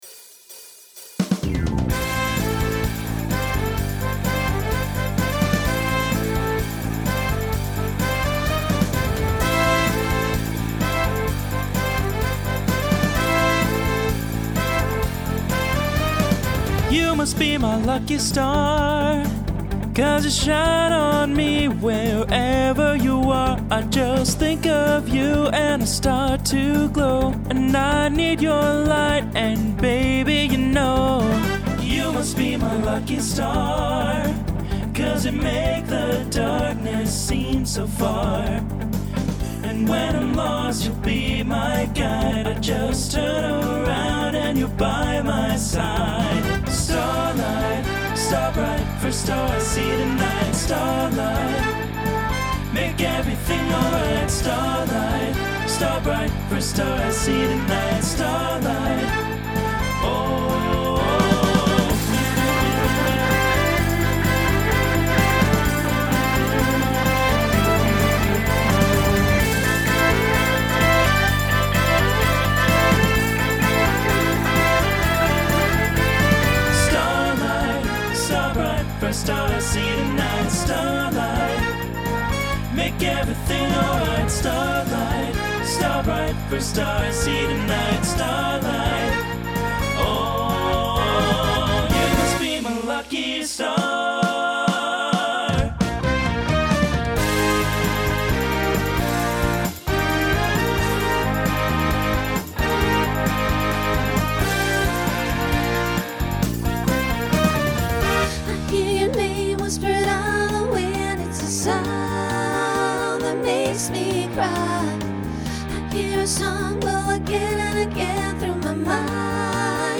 Starts TTB, then SSA, then SATB.
Genre Pop/Dance Instrumental combo
Transition Voicing Mixed